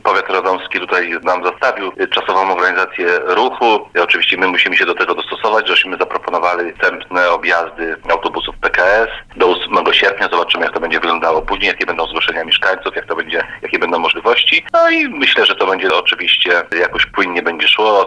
Więcej o tym Dariusz Bulski wójt gminy Kowala – Stępociny: